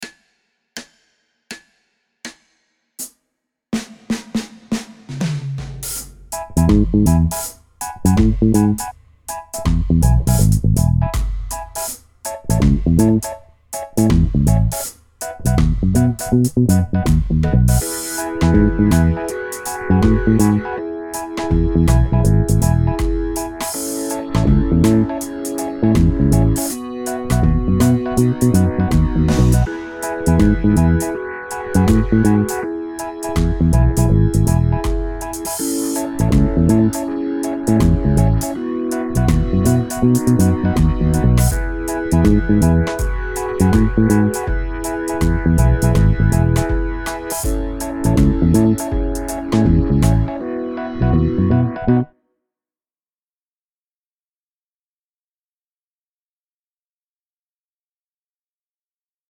Wem das jetzt noch zu theoretisch war, wird sich freuen nun eine „richtige“ praktische Anwendung zu finden: Achtung: Bei den zugehörigen Audio-Files gibt es bei diesem Beispiel die Besonderheit, dass – aufgrund eines längeren Auftakts vom Schlagzeug – zwei Takte eingezählt werden. Ihr müsst also zweimal bis vier zählen bevor es losgeht.
14-Example-4-Backing.mp3